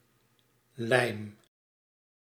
Ääntäminen
Synonyymit glu adhésif Ääntäminen France: IPA: [kɔl] Haettu sana löytyi näillä lähdekielillä: ranska Käännös Ääninäyte Substantiivit 1. lijm {m} 2. plakmiddel 3. kleefstof Suku: f .